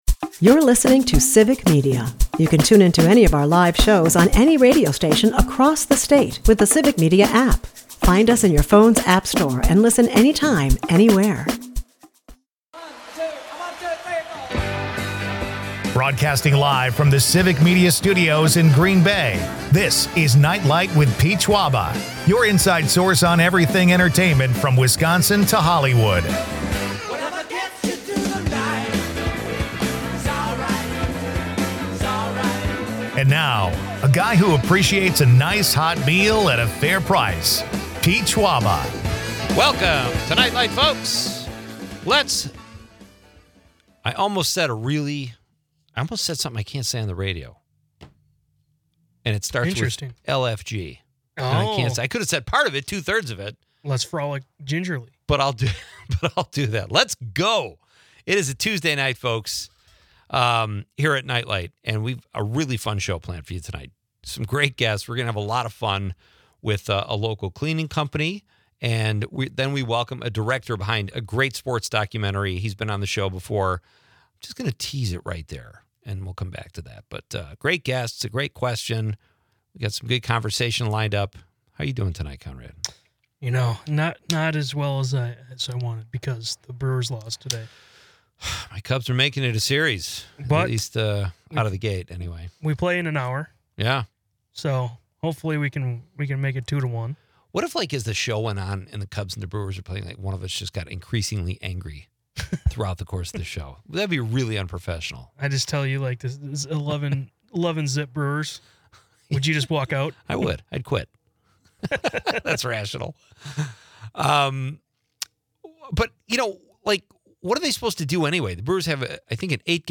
A mix of sports, cleanliness, and quirky discussions keeps listeners engaged.